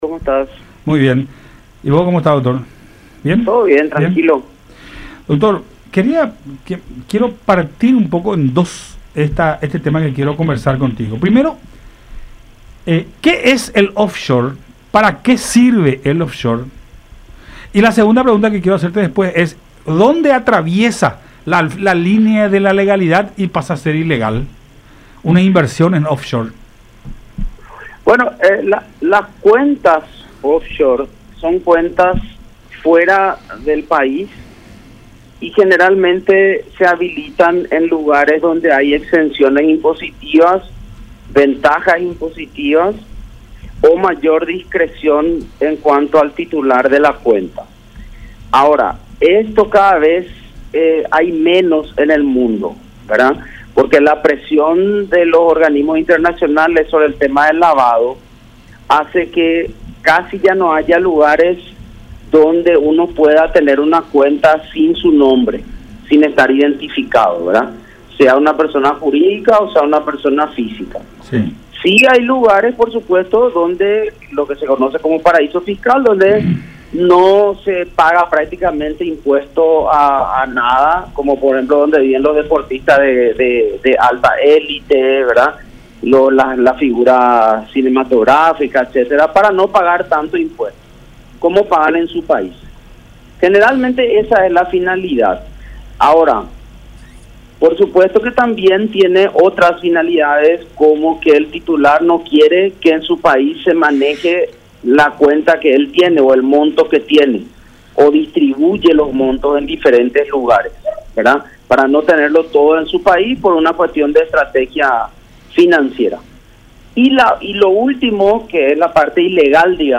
en conversación con Buenas Tardes La Unión